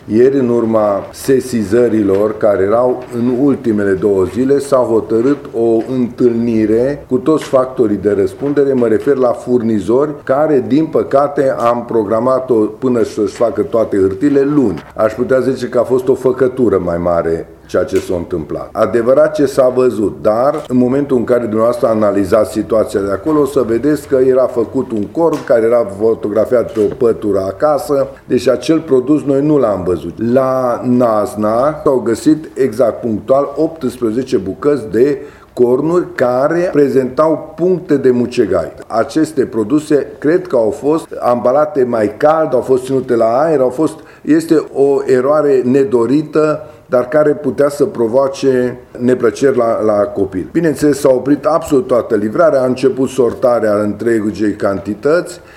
Comisarul șef Liviu Todoran a precizat că nu au fost găsite cornuri mucegăite.